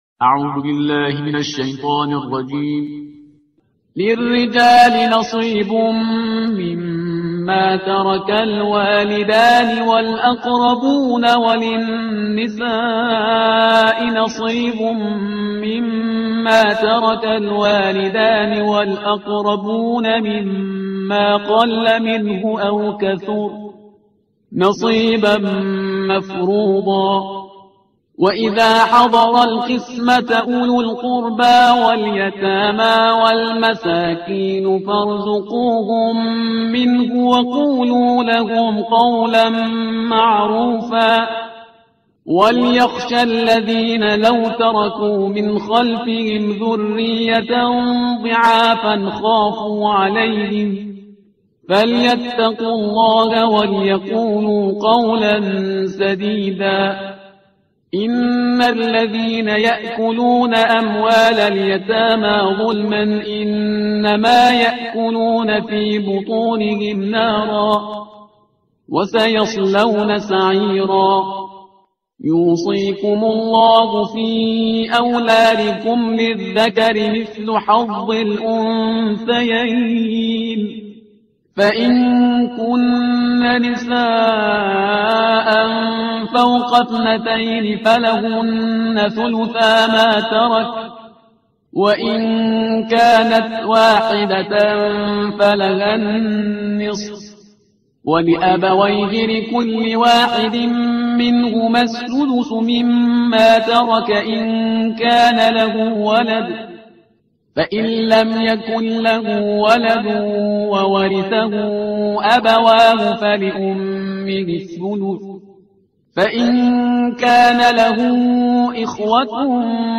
ترتیل صفحه 78 قرآن با صدای شهریار پرهیزگار